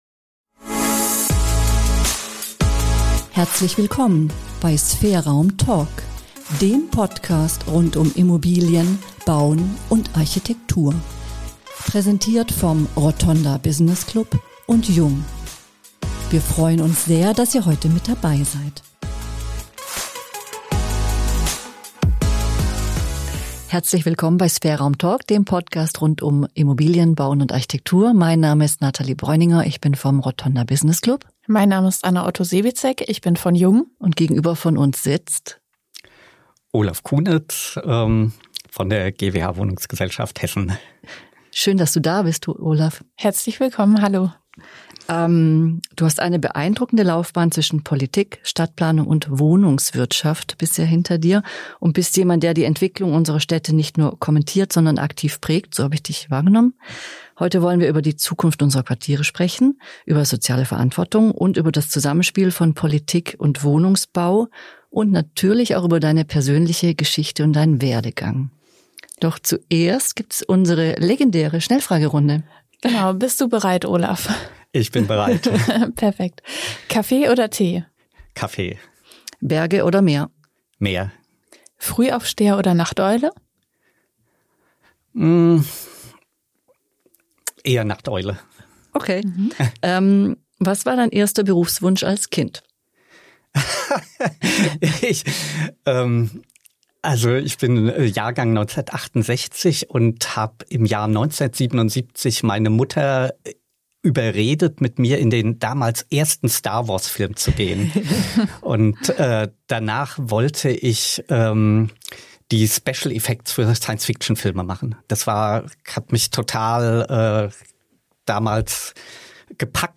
In der aktuellen Folge von SphereRaum Talk war Olaf Cunitz zu Gast – und das Gespräch hat deutlich gemacht, wie komplex, aber auch gestaltbar Stadt- und Quartiersentwicklung heute ist.